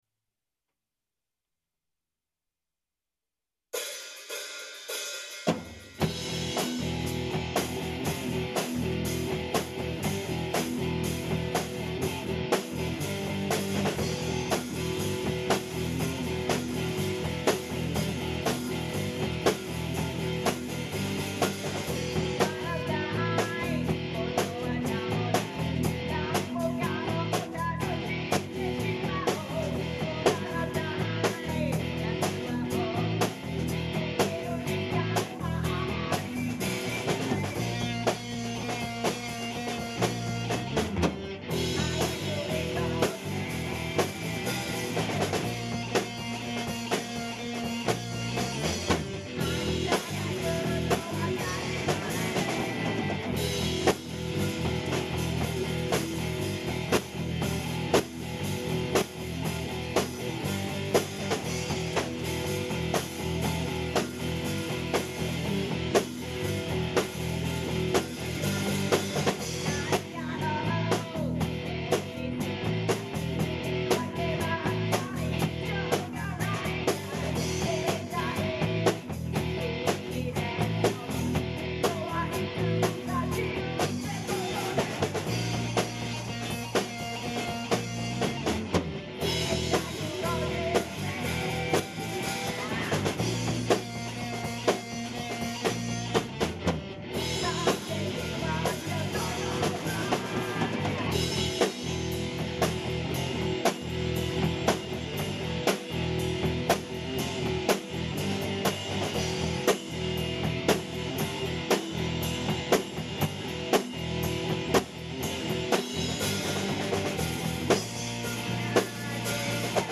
(demo)